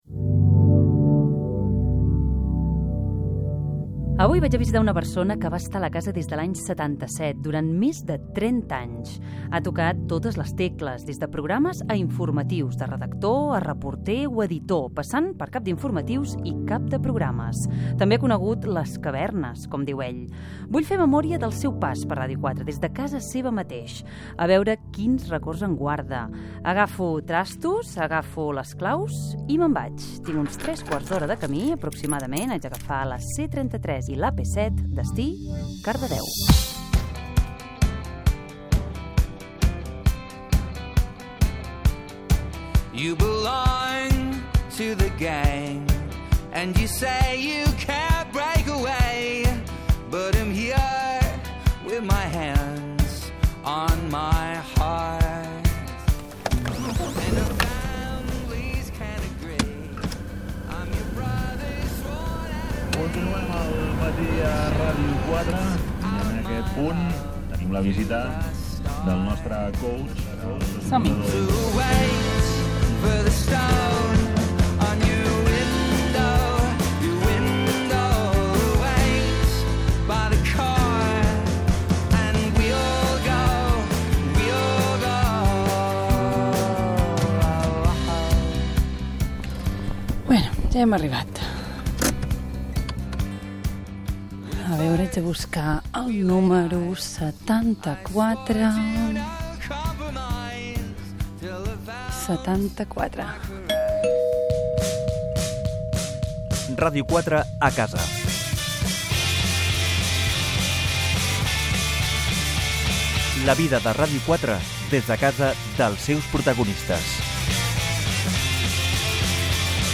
anada a Cardedeu, careta del programa
Gènere radiofònic Divulgació